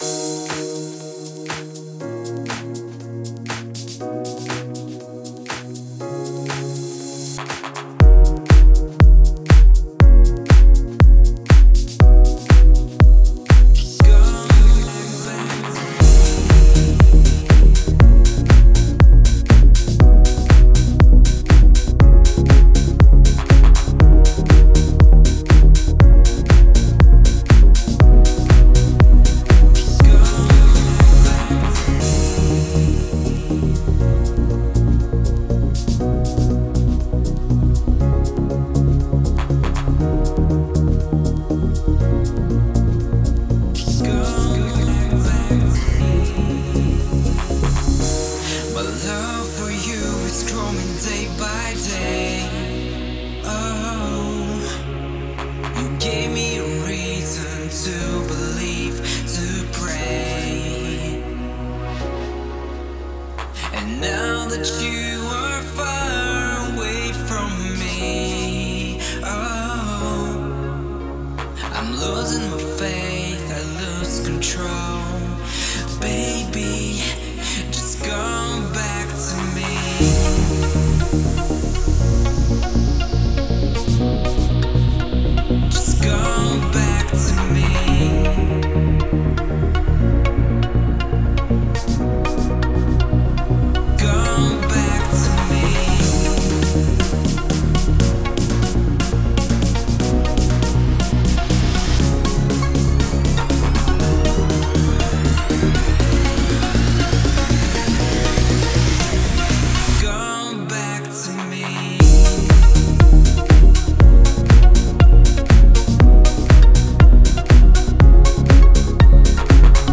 это трек в жанре поп с элементами R&B